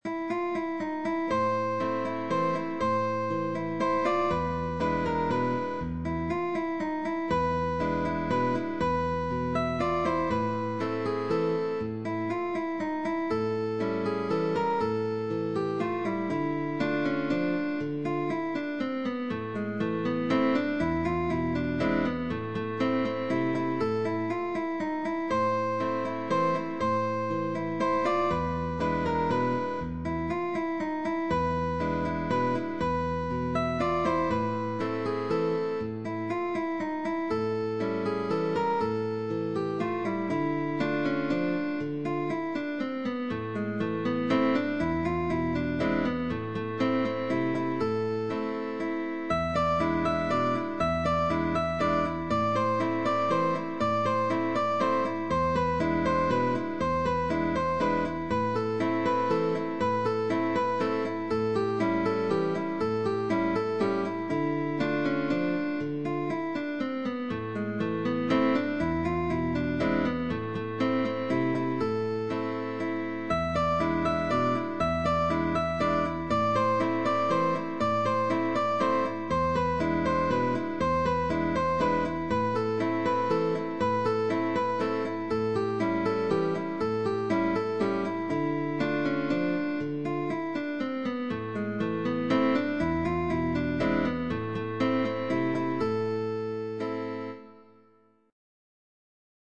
GUITAR DUO
Venezuelan waltz